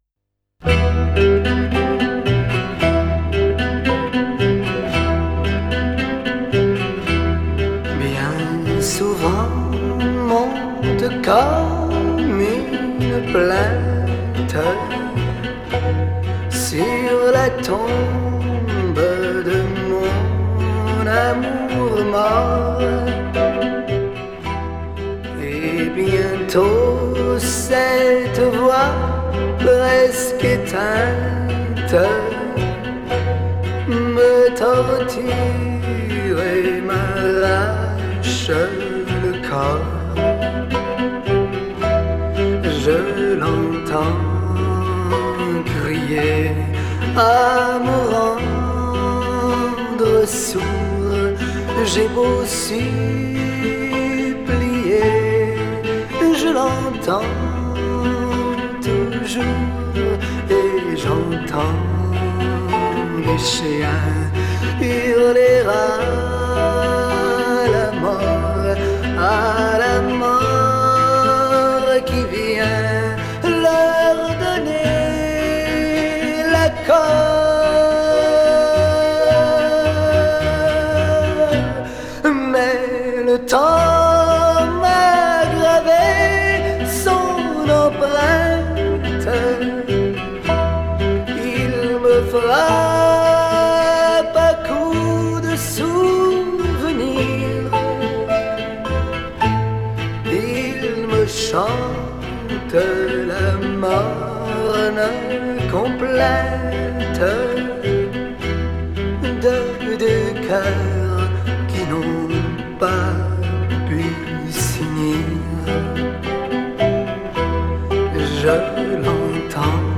Genre: Chanson, French Pop